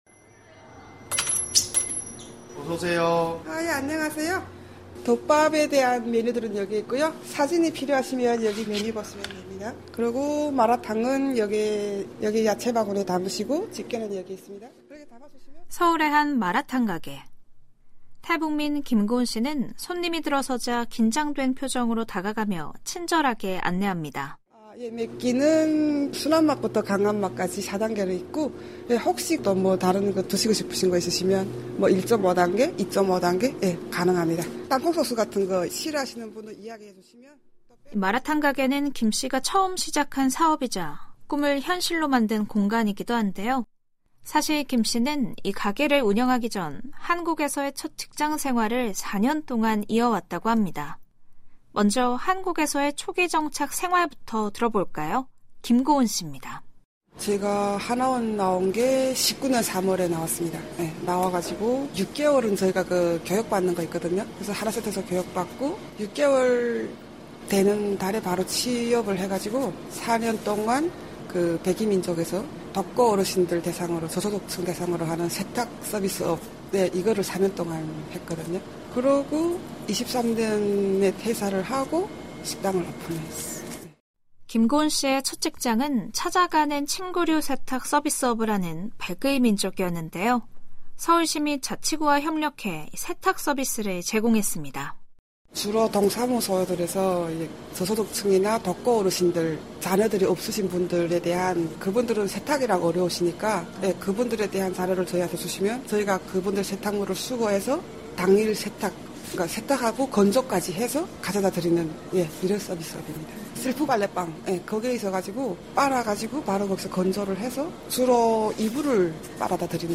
VOA 한국어 TV 프로그램 VOA 한국어 라디오 프로그램